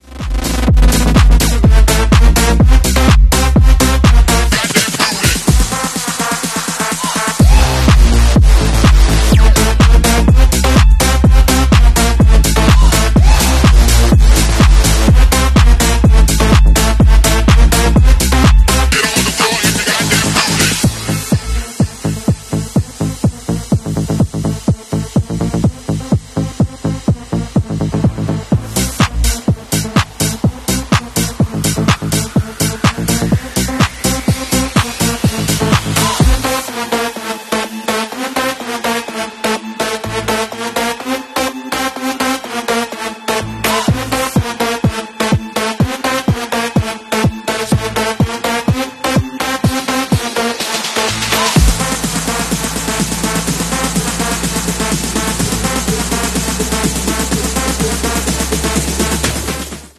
TOYOTA PRIUD 132DB 1 WOOFER sound effects free download